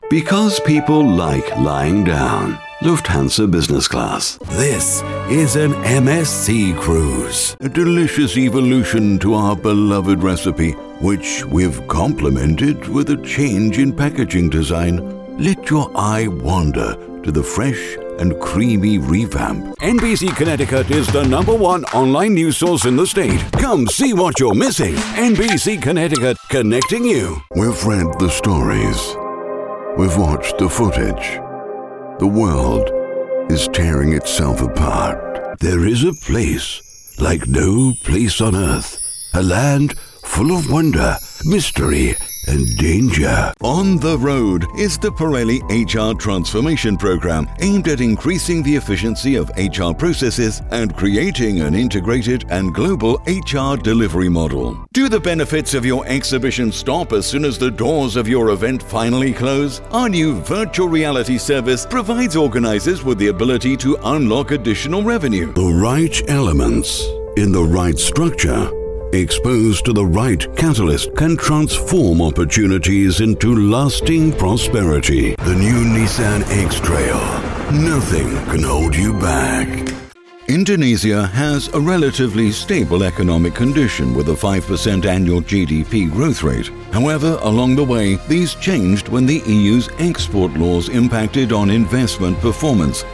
My voice is warm, strong, explicit and clear and is suitable for audio-books, e-learning, commercial, documentaries, telephone, video-games, commercials, animation, bussiness and more....